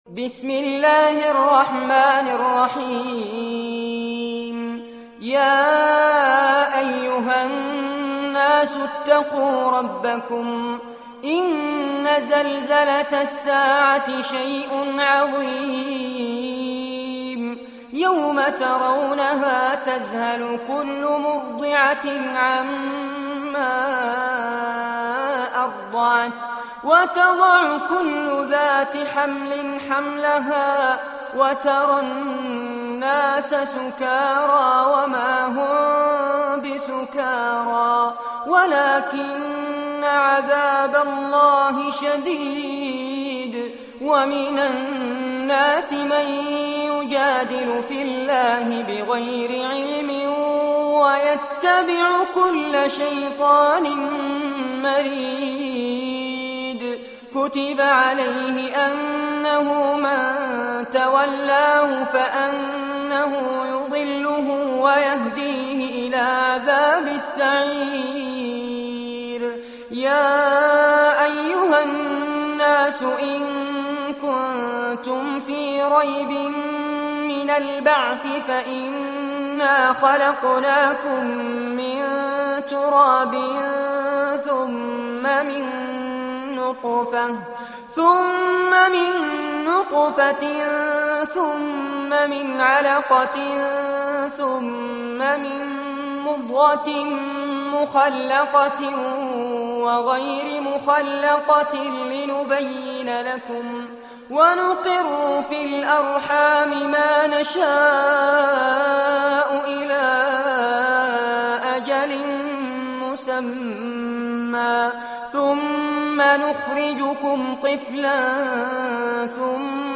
سورة الحج- المصحف المرتل كاملاً لفضيلة الشيخ فارس عباد جودة عالية - قسم أغســـــل قلــــبك 2